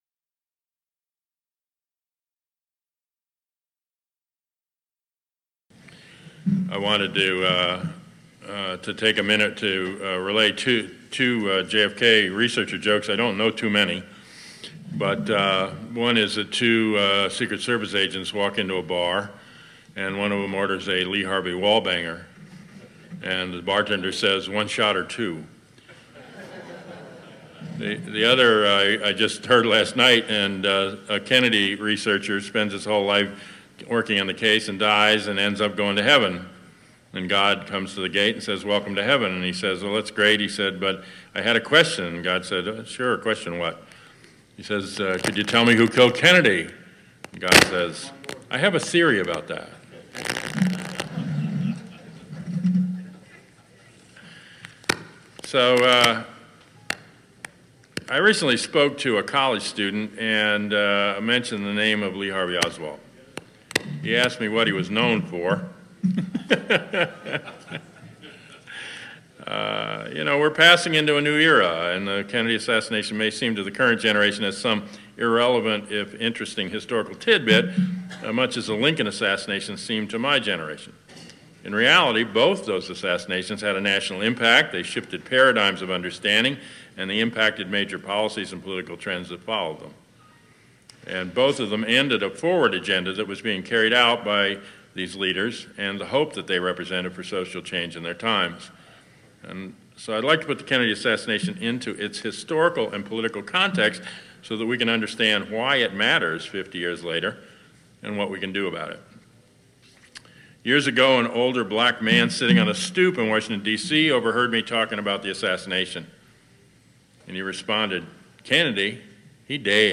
Coalition on Political Assassinations Closing Remarks Dallas, Texax 24 November 2013